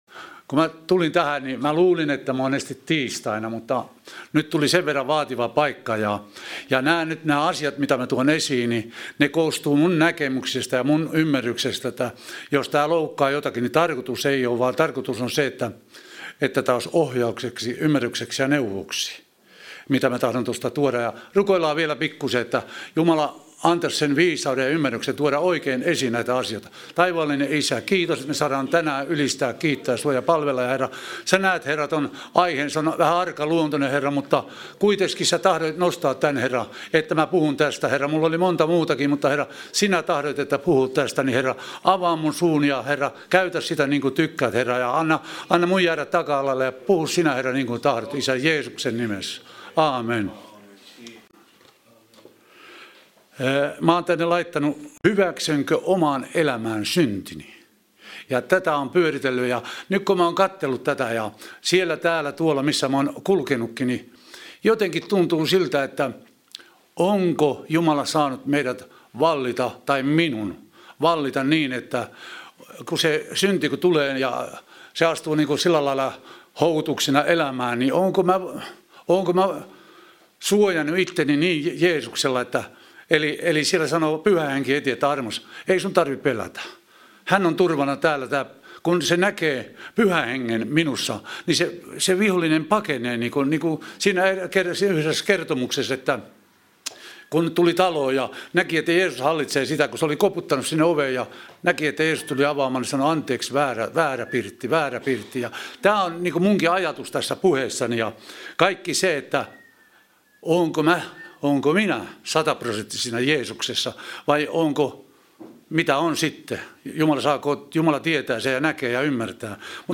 Vantaan Kotikirkko - Kuuntele puheita netissä